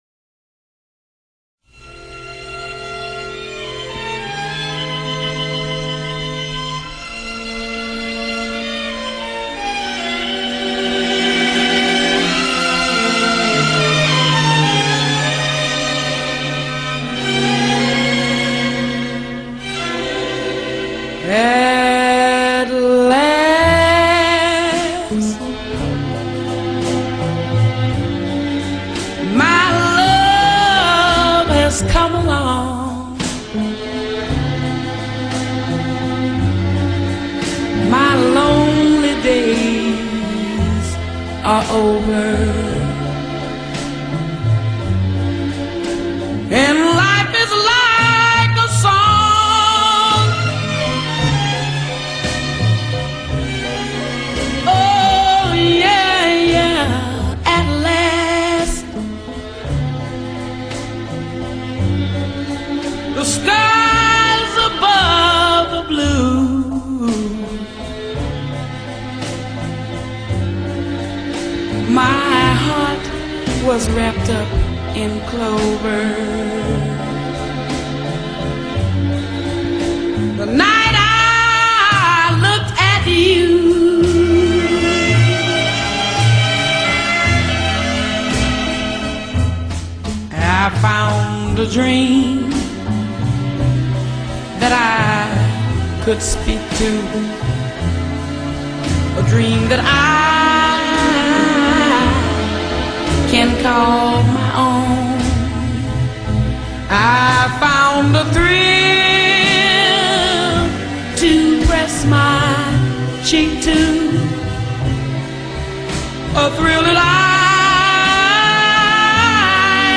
Tags1960s blues soul Western US